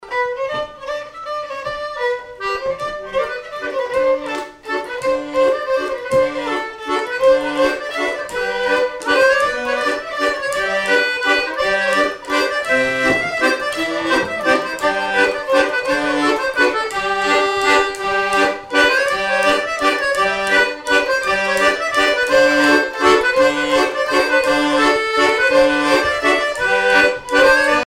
Localisation Miquelon-Langlade
danse : mazurka-valse
violon
Pièce musicale inédite